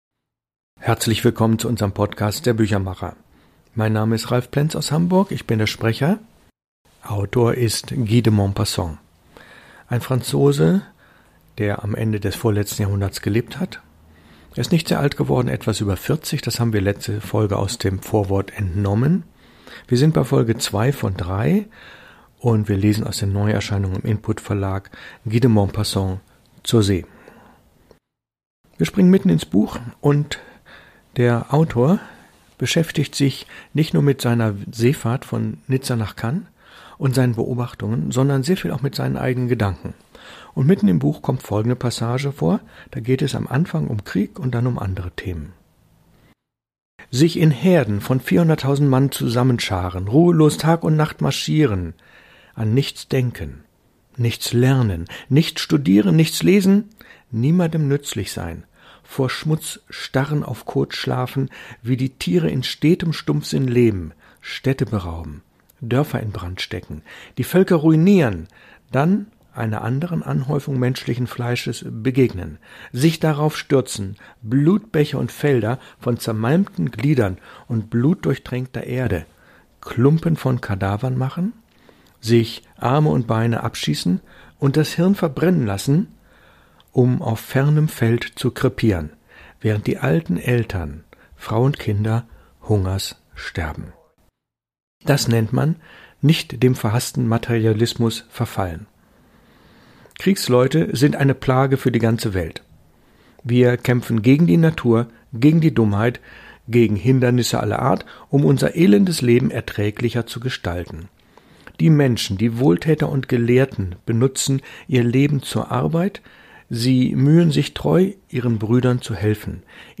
2. Lesung aus dem Buch von Guy de Maupassant (1888): „Zur See“, Folge 2 von 3